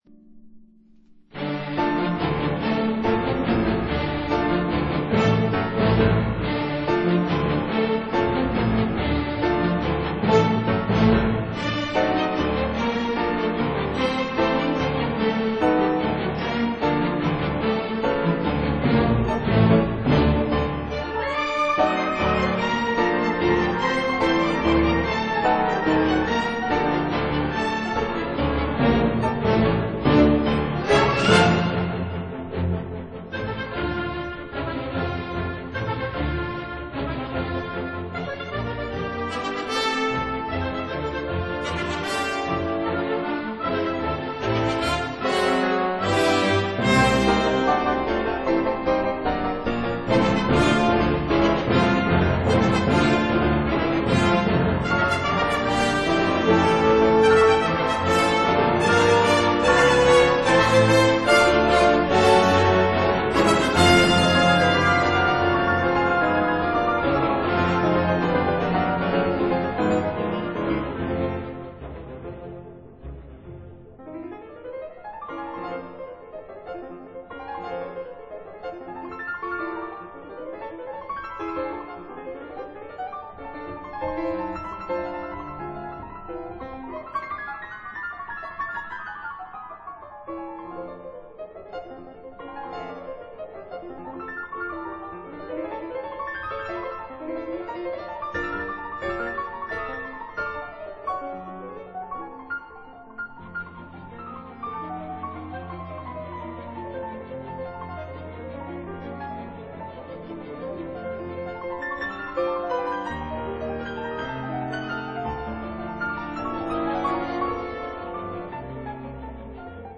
鋼琴協奏曲全集